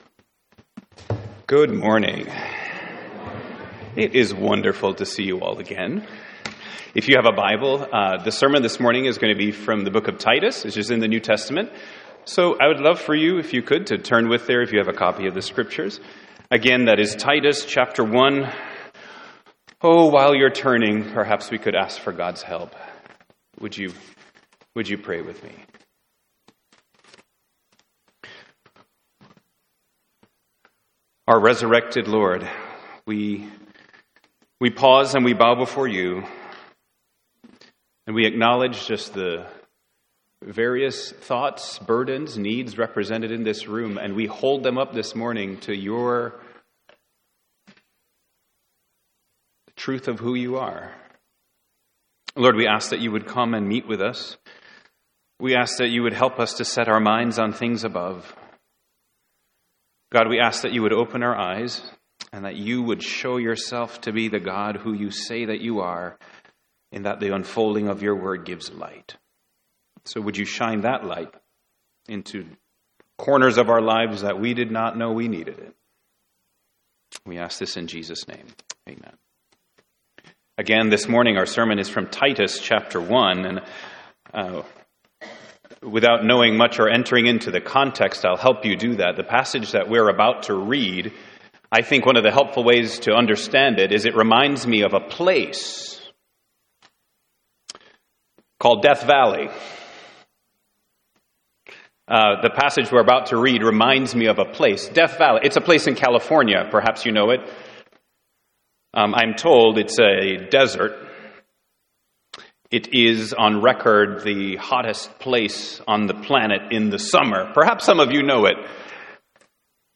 Titus — Audio Sermons — Brick Lane Community Church